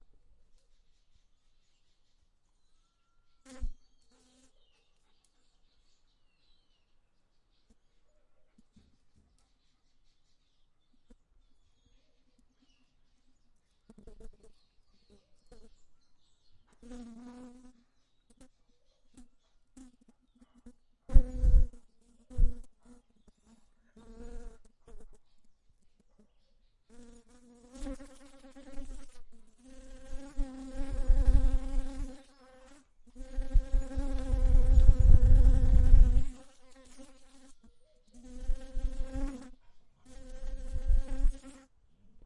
180081 蜜蜂靠窗
描述：嗡嗡声反对窗口的非洲弄糟蜂
Tag: 蜜蜂 窗口 OWI 恼人的 嗡嗡